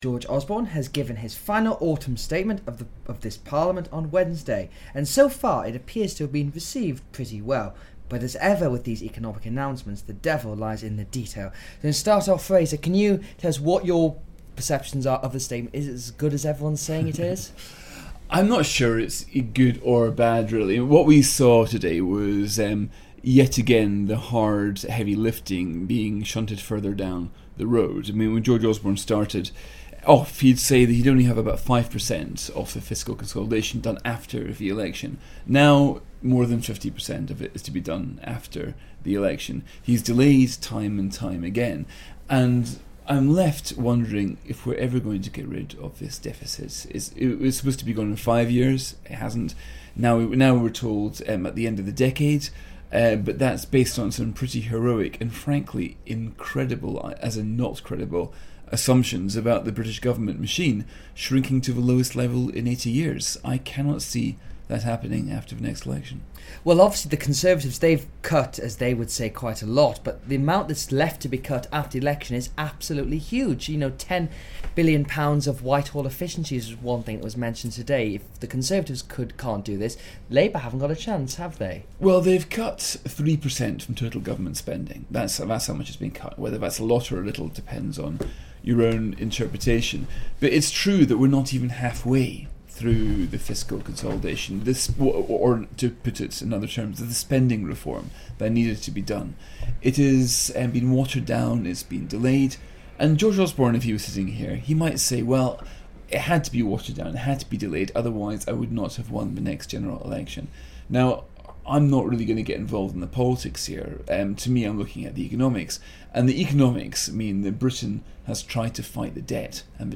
Fraser Nelson and James Forsyth discuss the Autumn Statement